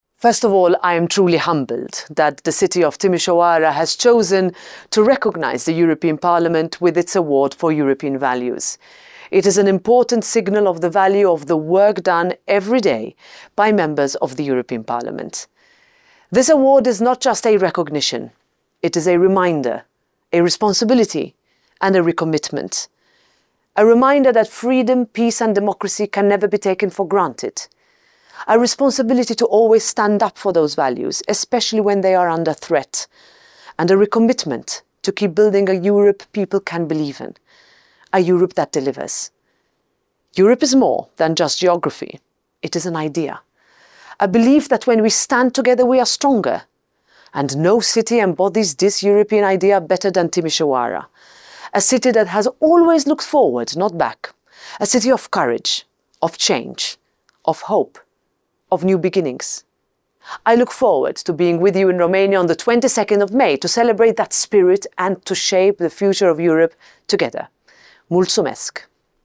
EU-Parlamentspräsidentin Roberta Metsola erklärte für Radio Temeswar: